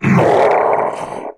spawners_mobs_mummy_death.2.ogg